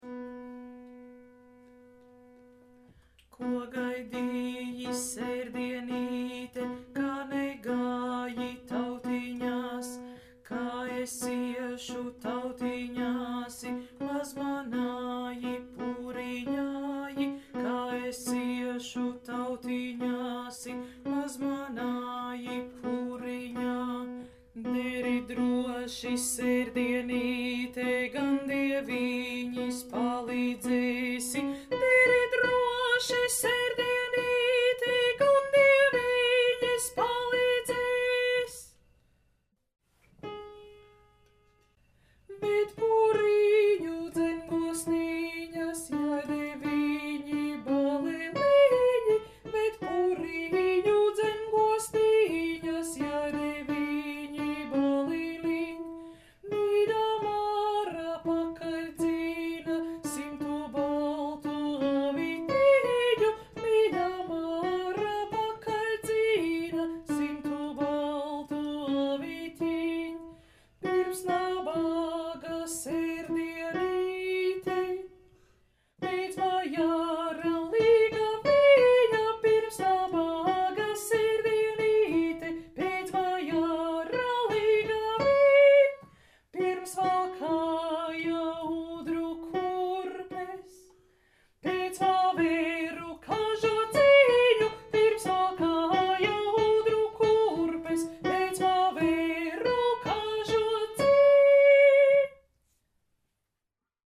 Alts I